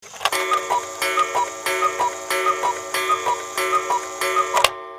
» Часы с кукушкой2 Размер: 81 кб